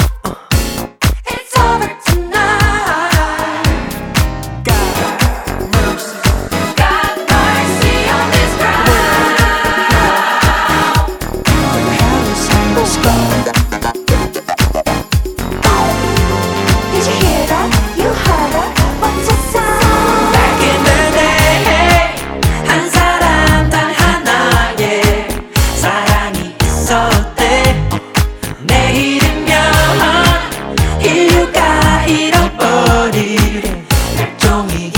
2025-07-14 Жанр: Танцевальные Длительность